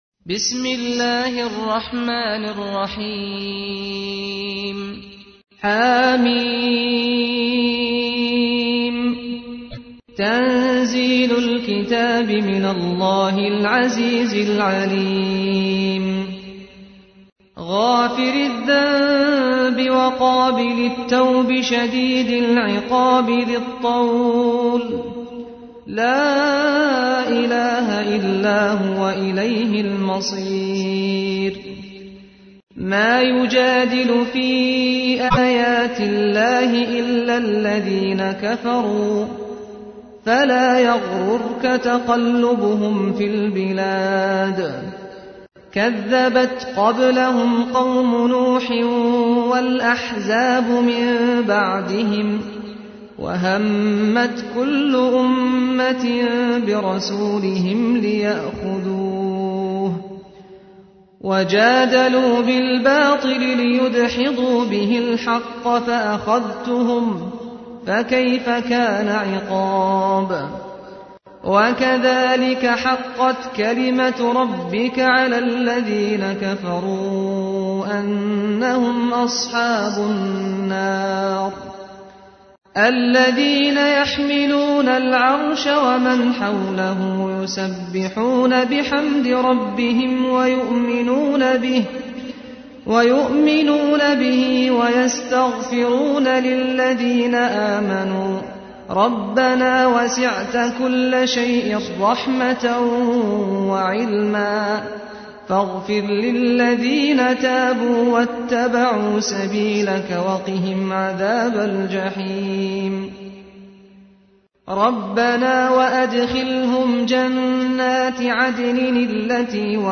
تحميل : 40. سورة غافر / القارئ سعد الغامدي / القرآن الكريم / موقع يا حسين